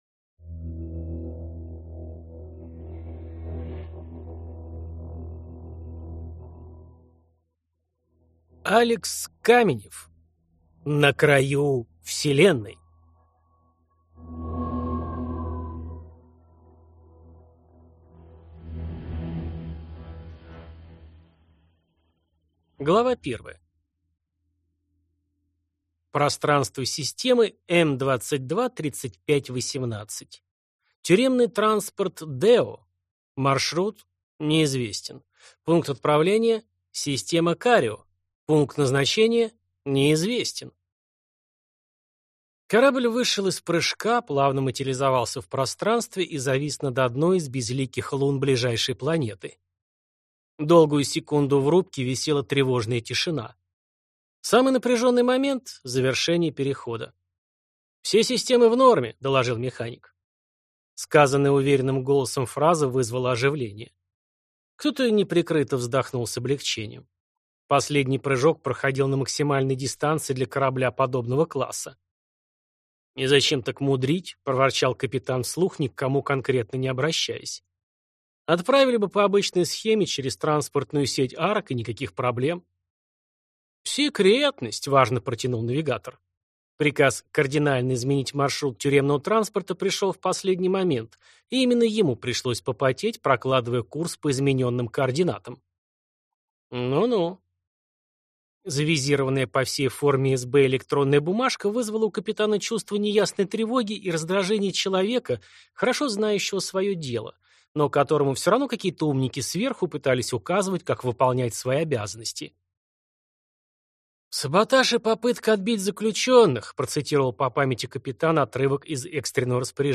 Аудиокнига На краю Вселенной | Библиотека аудиокниг